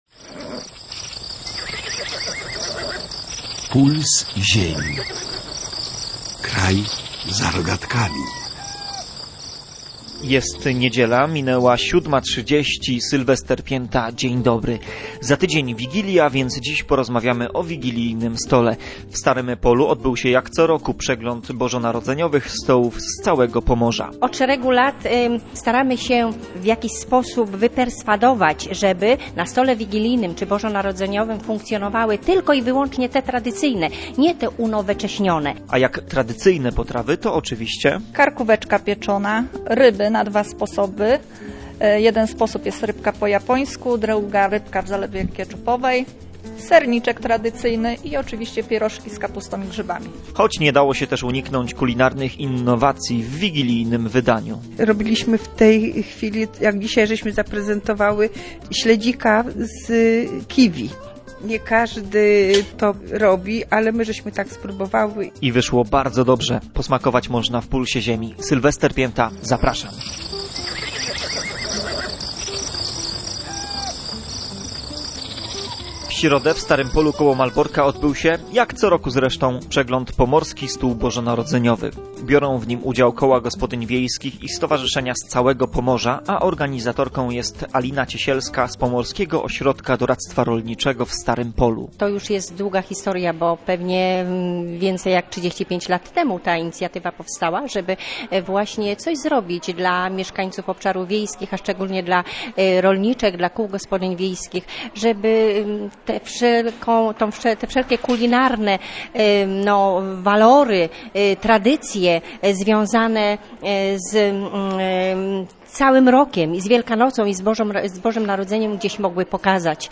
W środę w Starym Polu koło Malborka odbył się – jak co roku zresztą – przegląd Pomorski Stół Bożonarodzeniowy.